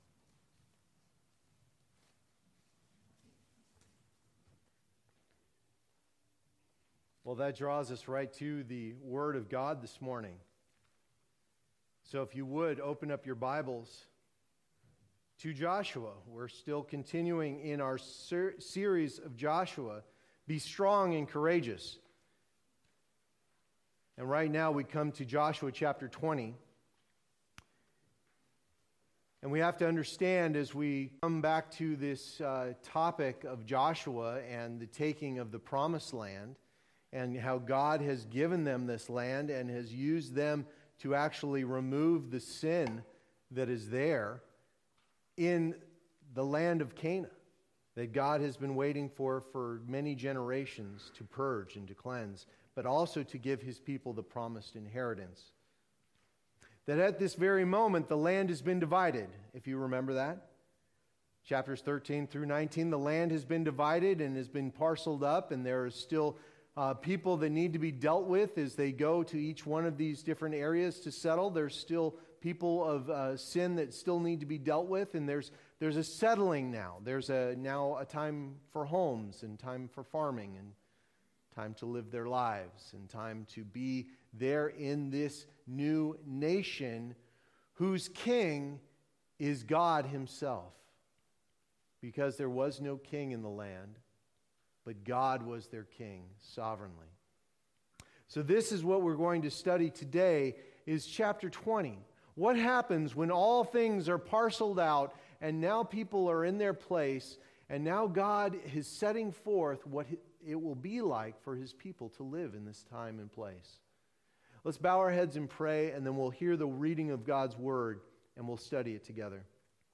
Be Strong and Courageous Passage: Joshua 20:1-9 Services: Sunday Morning Service Download Files Notes Topics